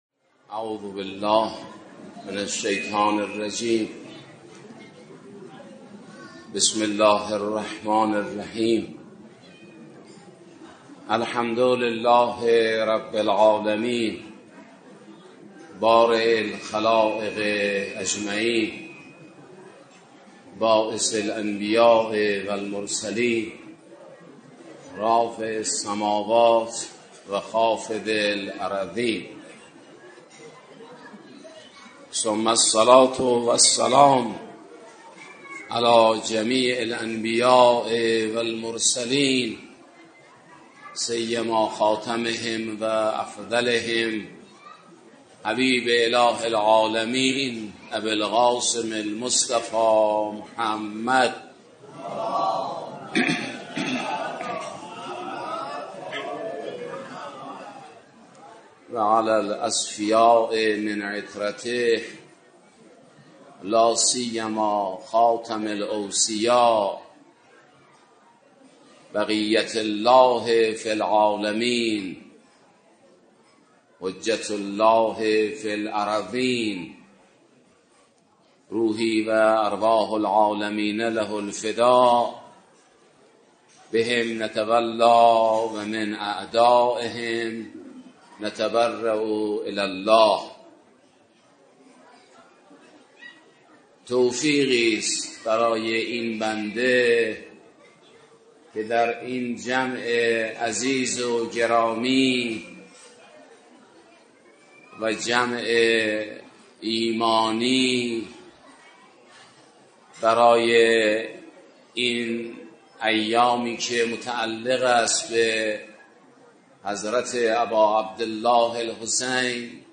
در جمعی از مؤمنان و ارادتمندان اهل بیت عصمت و طهارت